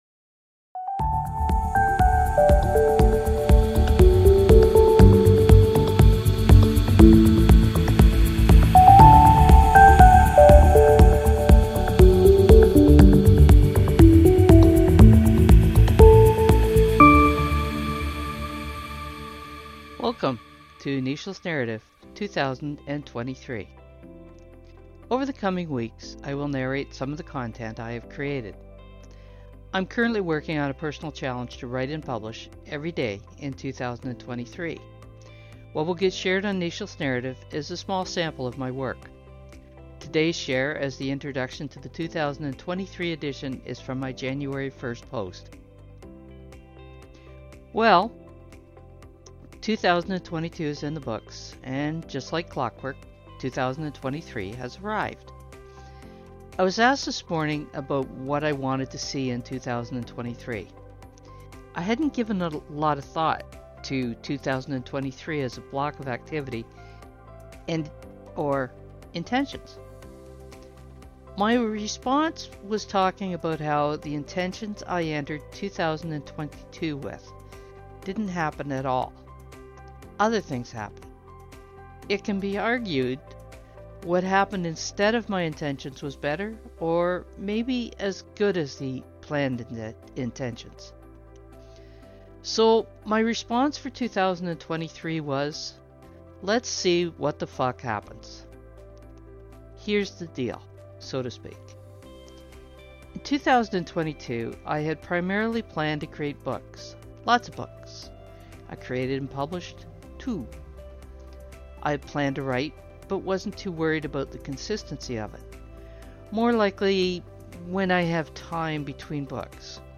Over the coming weeks I will narrate some of the content I have created.